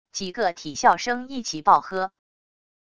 几个体校生一起爆喝wav音频